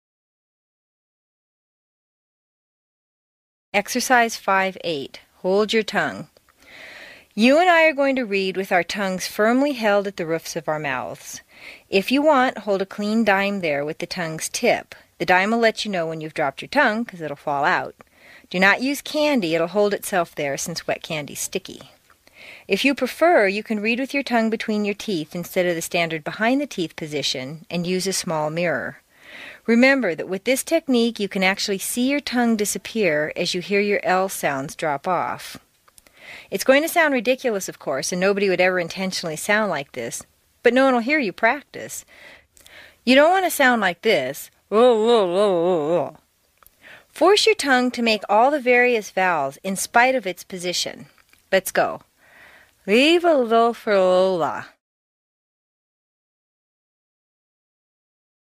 在线英语听力室美式英语正音训练第74期:练习5(8)的听力文件下载,详细解析美式语音语调，讲解美式发音的阶梯性语调训练方法，全方位了解美式发音的技巧与方法，练就一口纯正的美式发音！